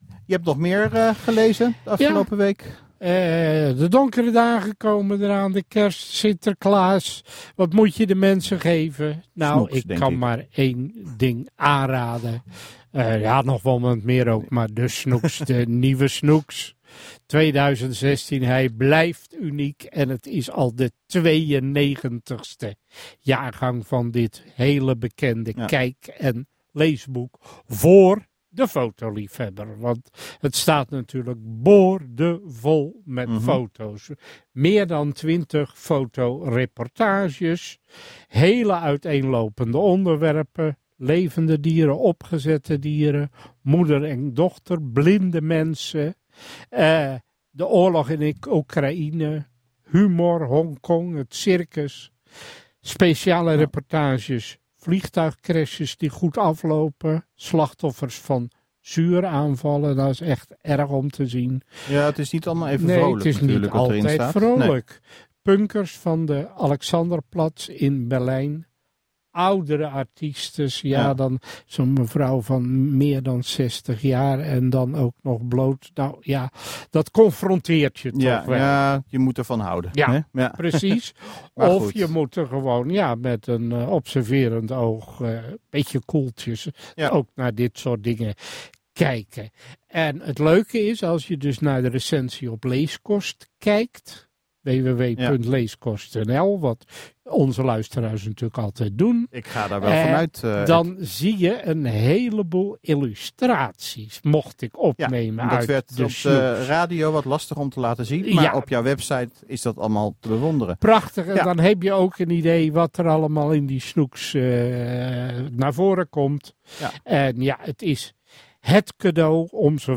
Dit boek is op woensdag 7 oktober 2015 besproken in het programma Puur Cultuur van MeerRadio.